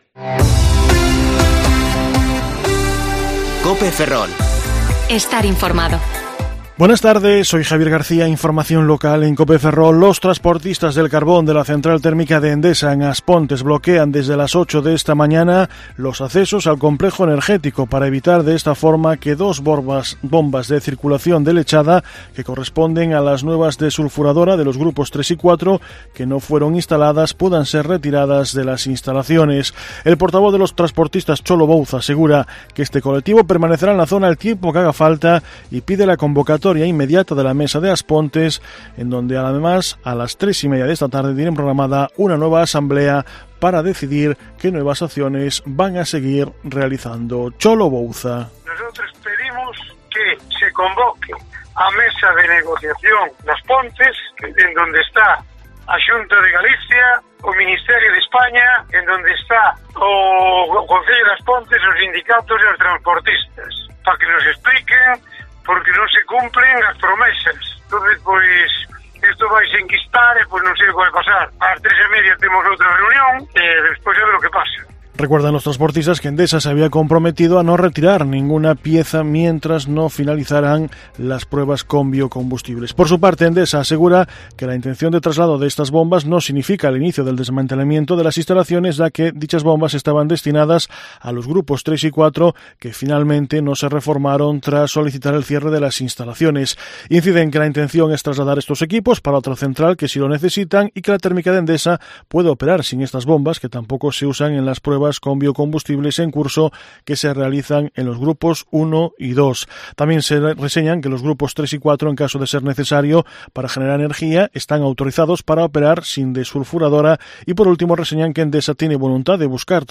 Informativo Mediodía COPE Ferrol 22/10/2020 (De 14,20 a 14,30 horas)